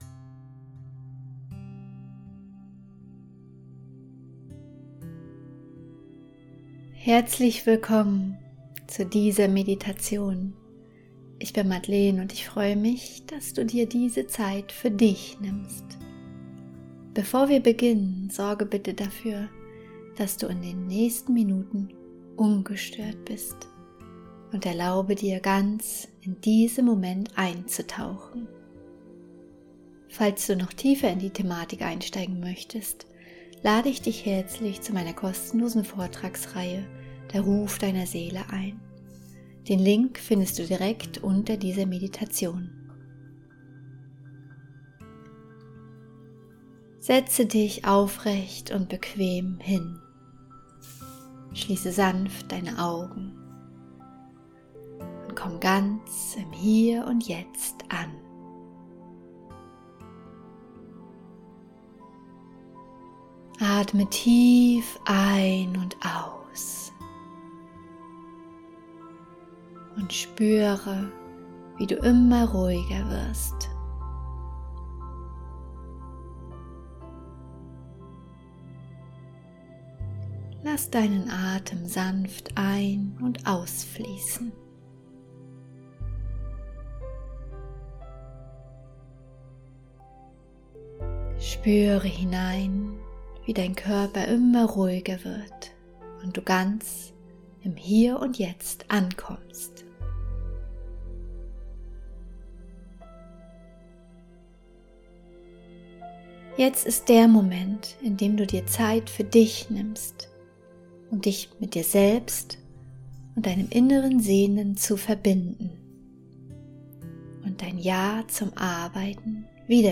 Willkommen zu dieser 15-minütigen geführten Meditation für dein inneres JA zur Arbeit.